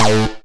tekTTE63032acid-A.wav